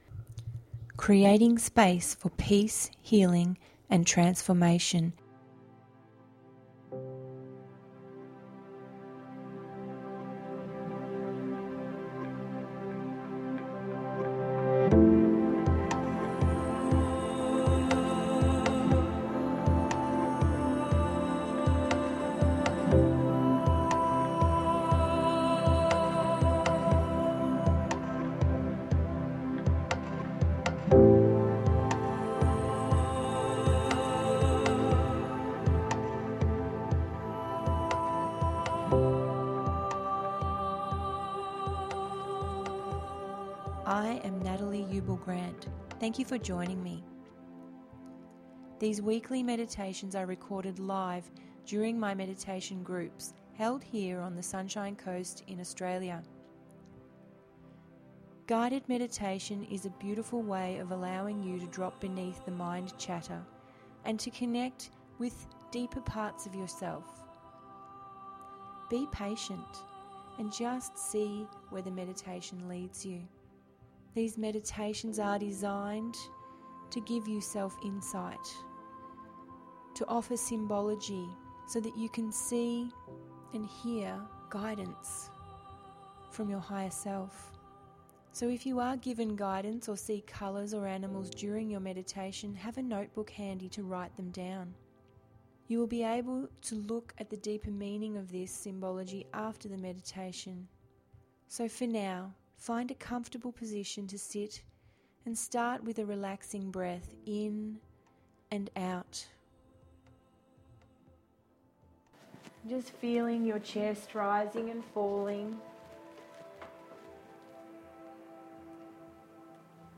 Meditation duration approx. 18 mins